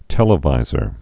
(tĕlə-vīzər)